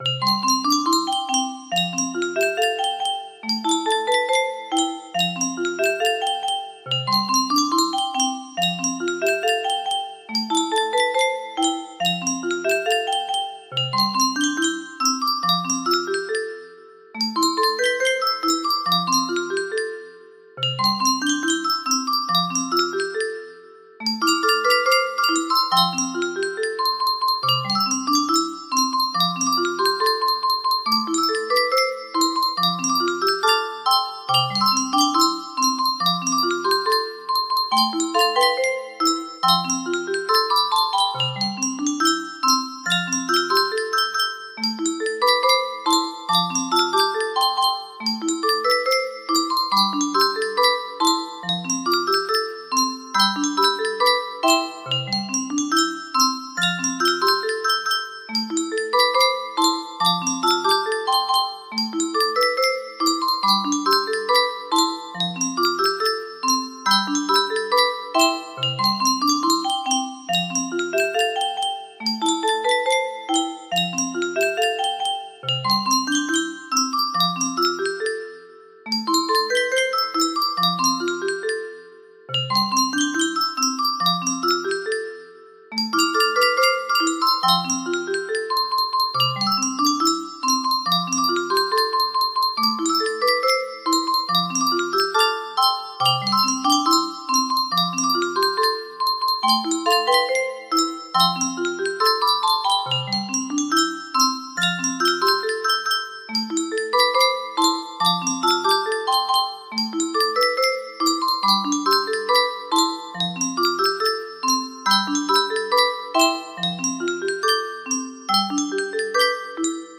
unpredictable... music box melody
Full range 60